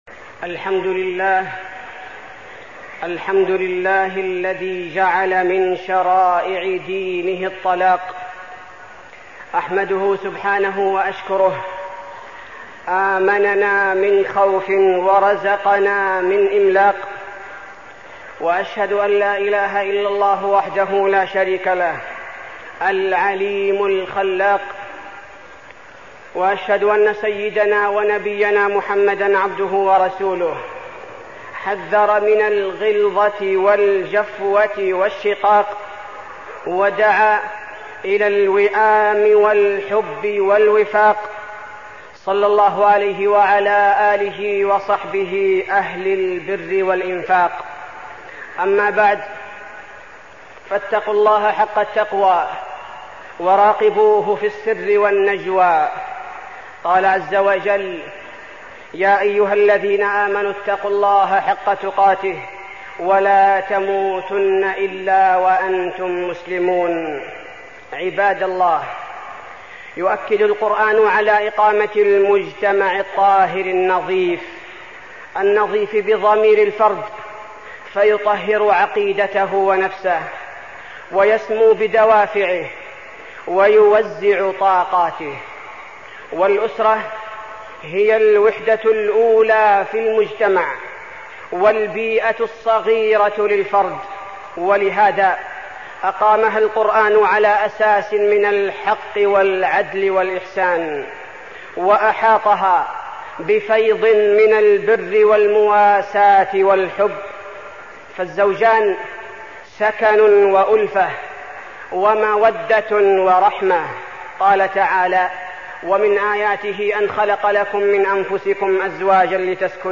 تاريخ النشر ١٠ ذو القعدة ١٤١٦ هـ المكان: المسجد النبوي الشيخ: فضيلة الشيخ عبدالباري الثبيتي فضيلة الشيخ عبدالباري الثبيتي الطلاق